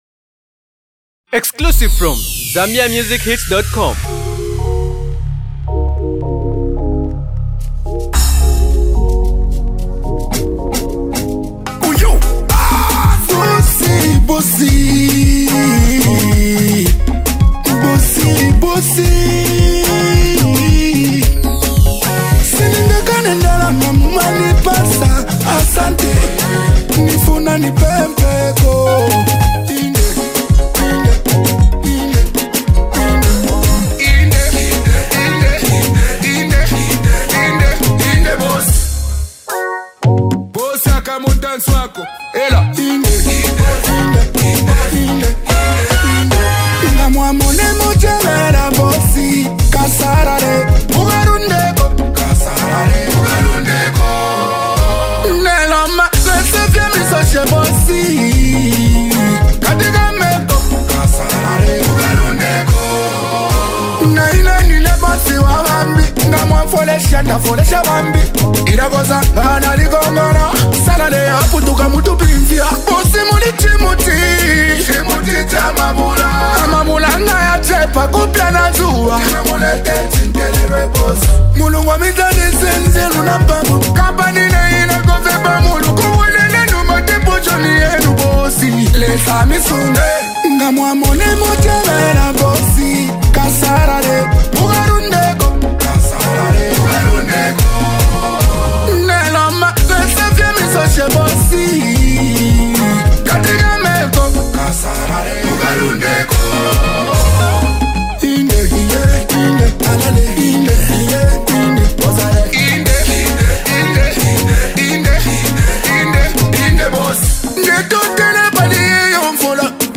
a December banger
Well, this jam is set to be banged on a full-blast volume.